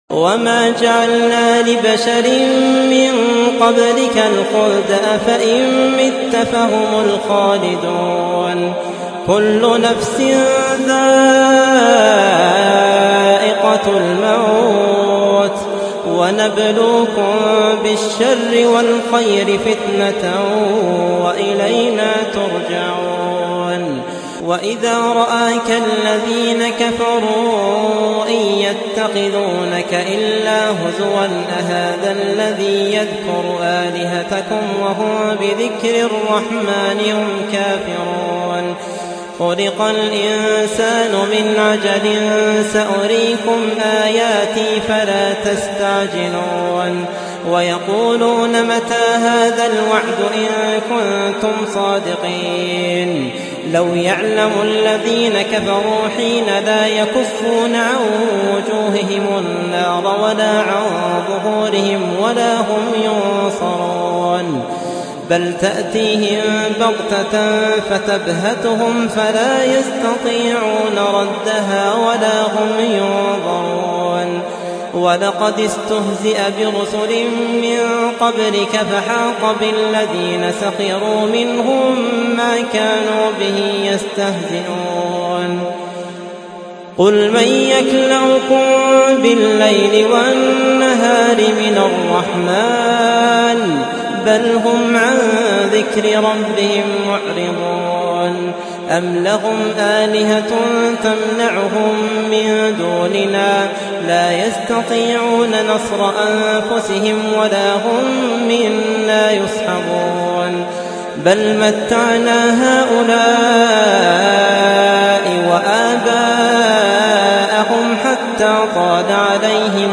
هذه قراءة صافية وبجودة جيده
ماشاء الله .. صوت رائع ..
مشاءالله تلاوة رائعة دون تكلف
ماشاء الله الصوت رهيب..